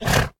donkey